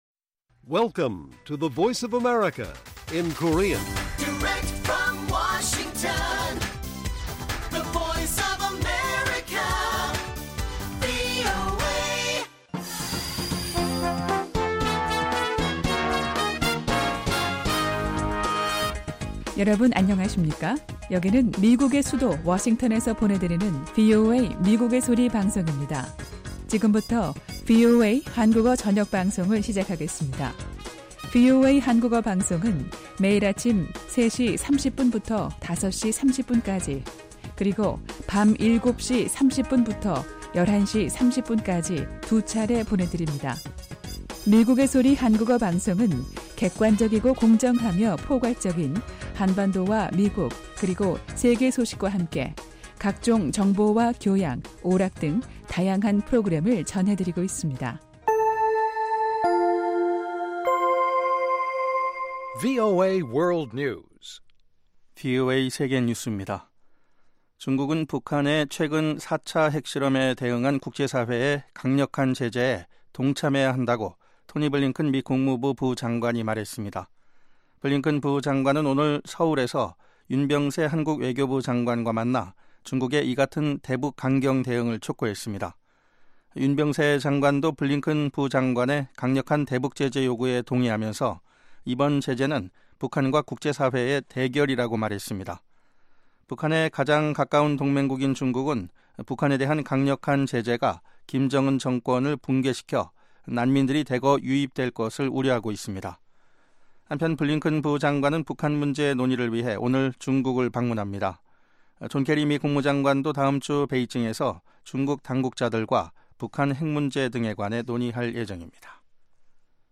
VOA 한국어 방송의 간판 뉴스 프로그램 '뉴스 투데이' 1부입니다.